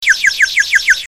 clock11.ogg